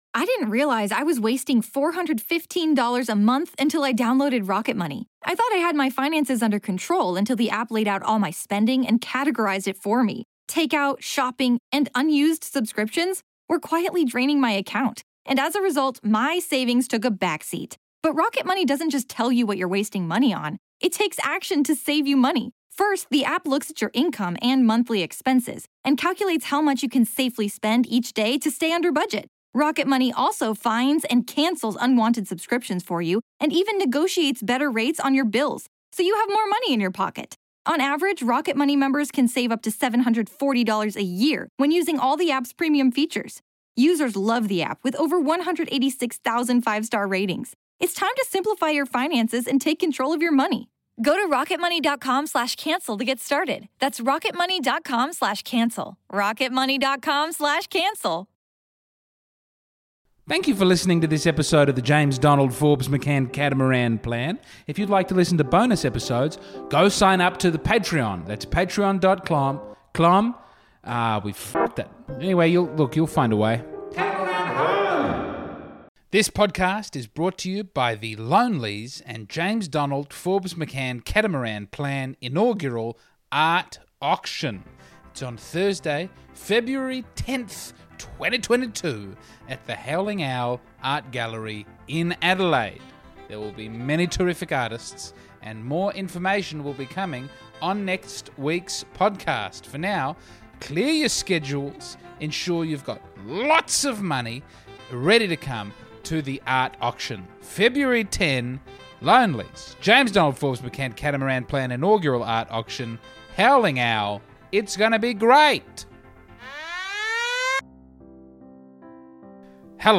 That is Teezo Touchdown saying 'professional'.